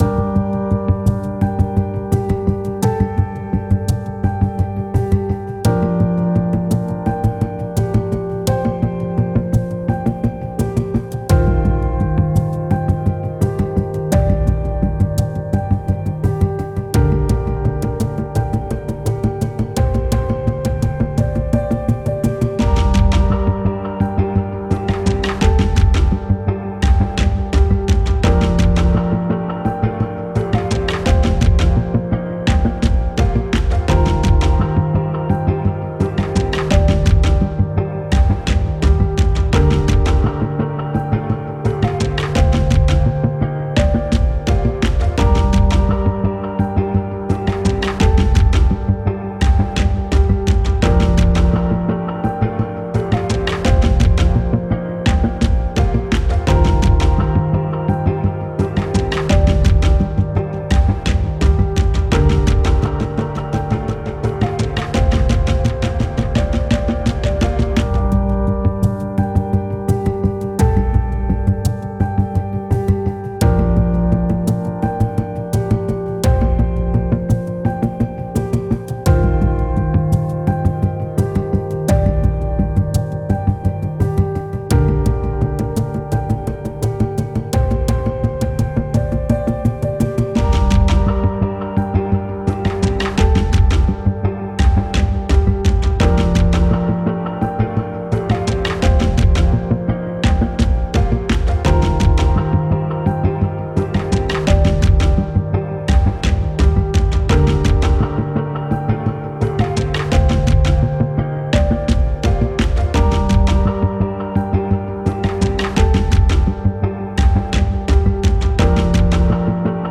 une atmosphère oppressante et cinématique